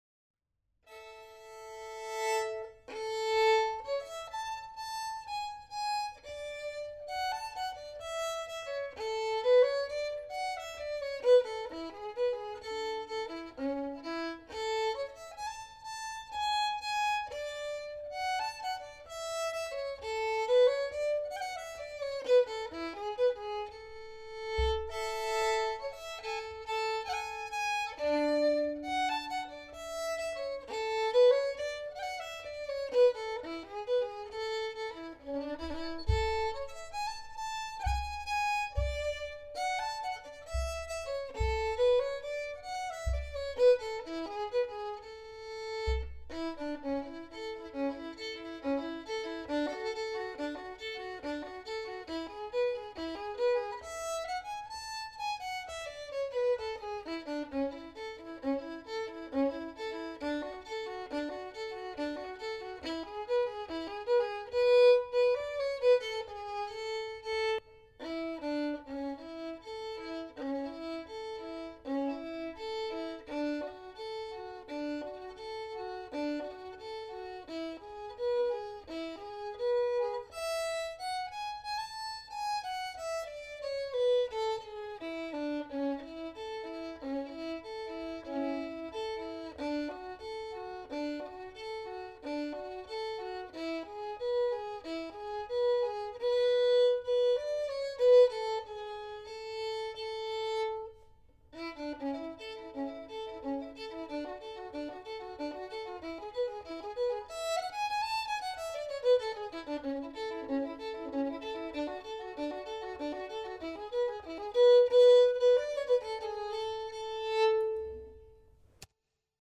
Låttyp: polska.